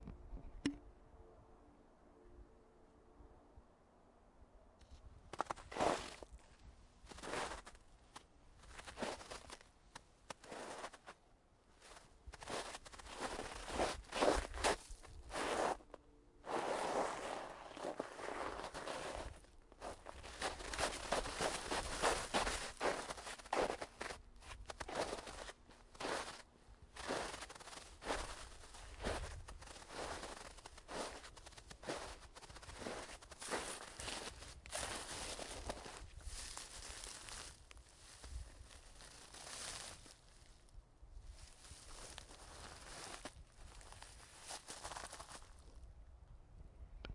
描述：walking footsteps running
标签： running footsteps walking
声道立体声